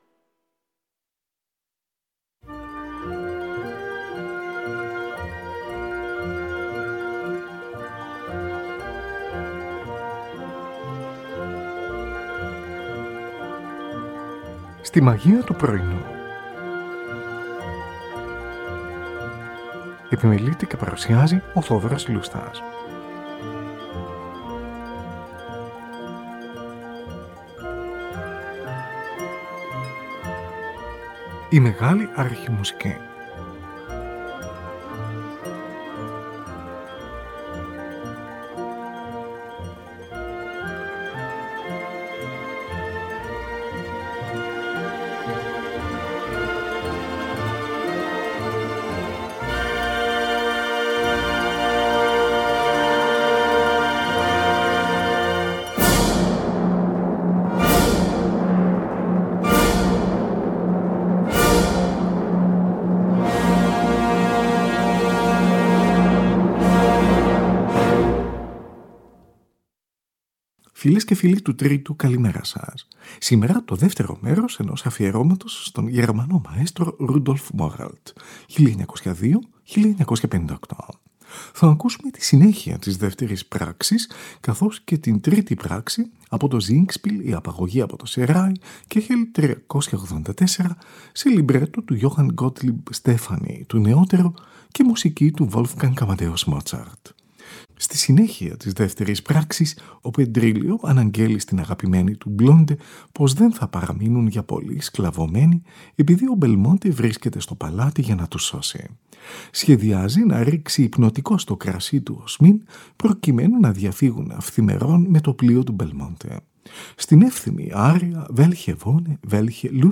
Wolfgang Amadeus Mozart: Η απαγωγή από το Σεράι, Κ.384, τρίπρακτο singspiel, σε λιμπρέτο του Johann Gottlieb Stephanie, του νεότερου.
Λαμβάνουν μέρος οι καλλιτέχνες: Belmonte, ένας Ισπανός ευγενής, ο τενόρος Anton Dermota. Konstanze, αγαπημένη του Belmonte, η υψίφωνος Elisabeth Schwarzkopf.
Τη Χορωδία της Βιεννέζικης Ραδιοφωνίας και τη Συμφωνική της Αυστριακής Ραδιοφωνίας διευθύνει ο Rudolf Moralt. Ραδιοφωνική ηχογράφηση σε studio – με λίγες περικοπές – το 1945.